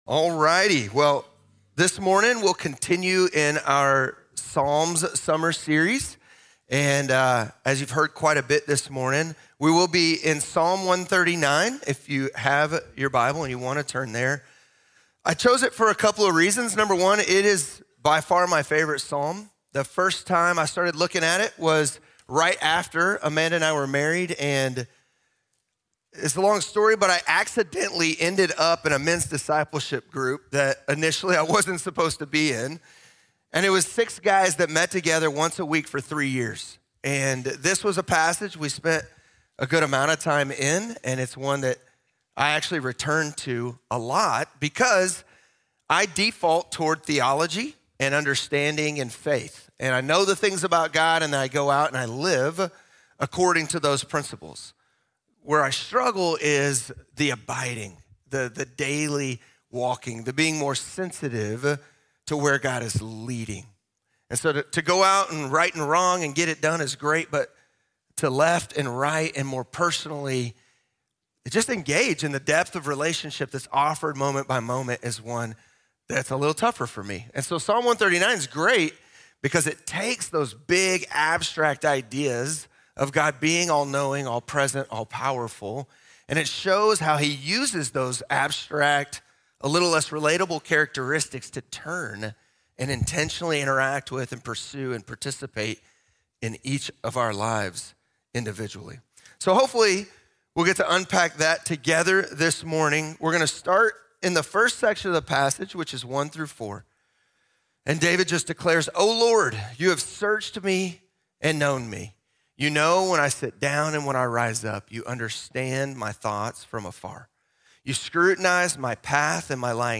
Psalm 139: Transcendent & Intimate | Sermon | Grace Bible Church